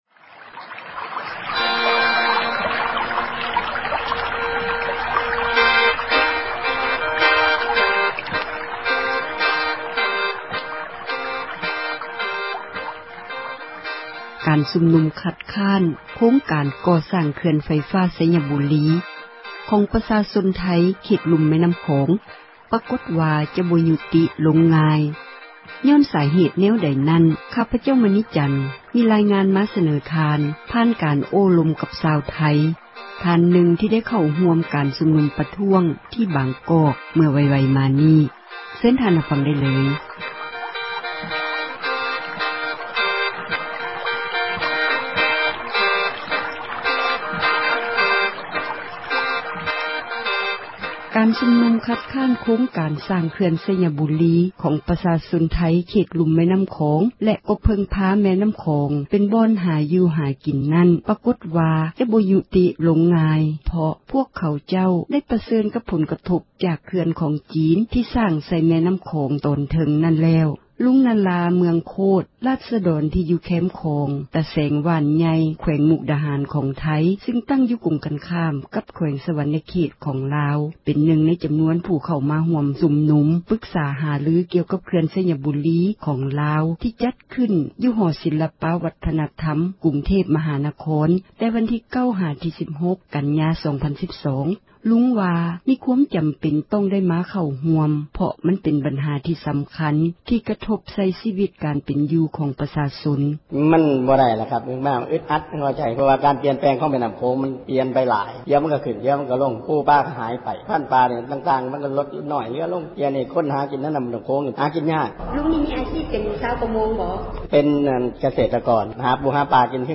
ເອເຊັຍເສຣີສັມພາດ ຊາວໄທຊຸມນຸມຕ້ານເຂື່ອນ